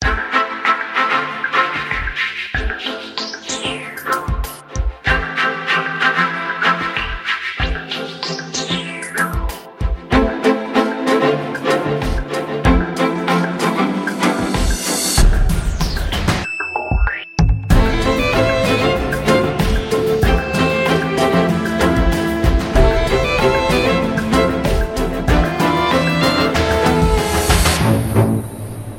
• Качество: 128, Stereo
скрипка
Классная стандартная мелодия на звонок